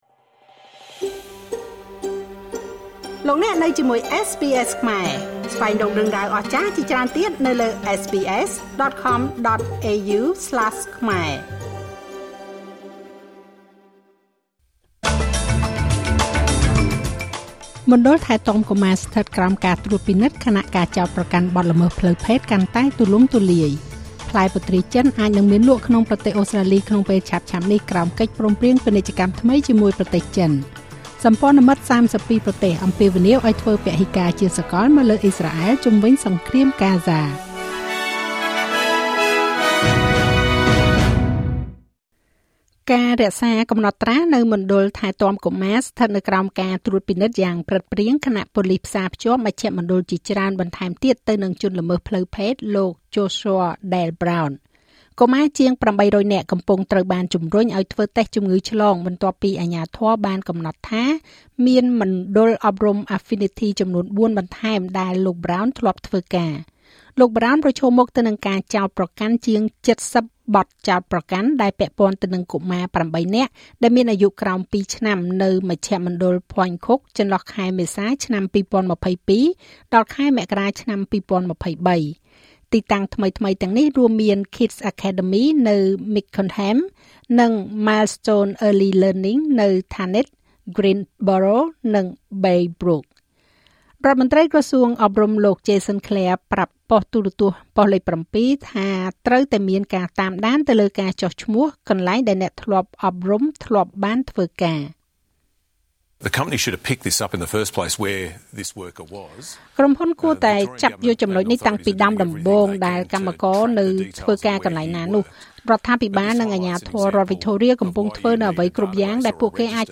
នាទីព័ត៌មានរបស់SBSខ្មែរ សម្រាប់ថ្ងៃពុធ ទី១៦ ខែកក្កដា ឆ្នាំ២០២៥